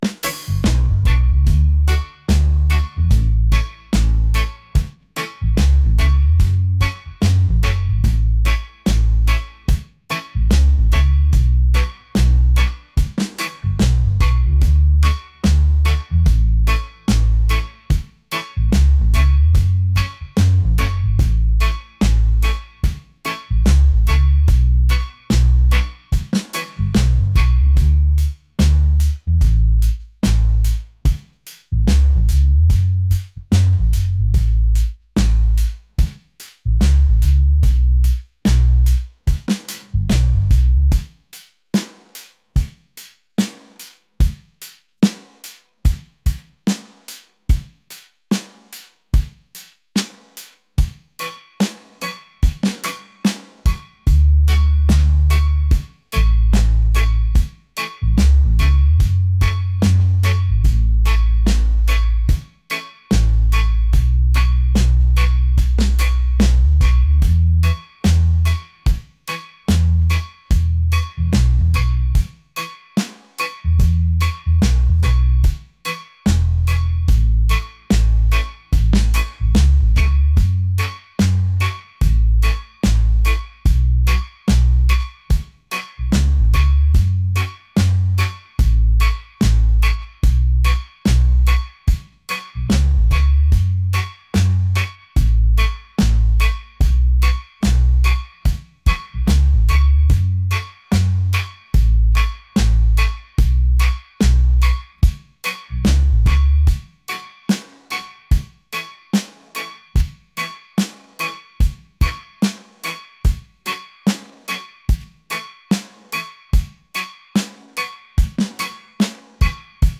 groovy | laid-back | reggae